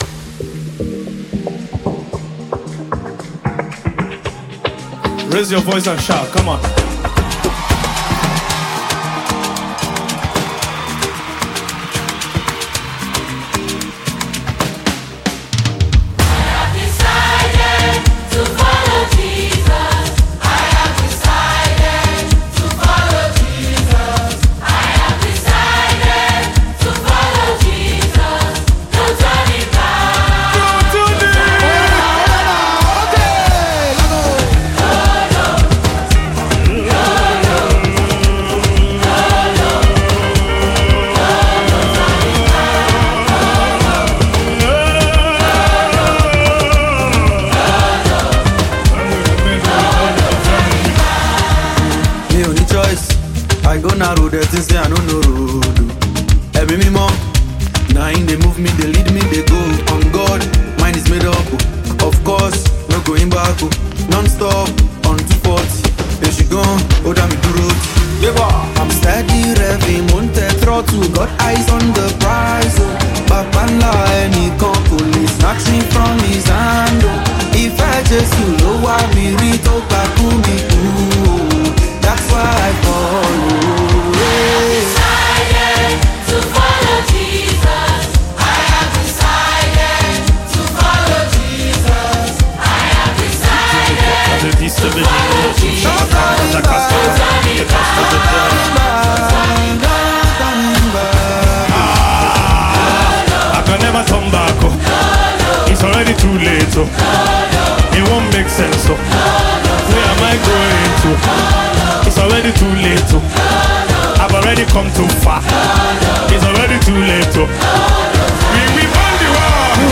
African Gospel Music
vibrant instrumentals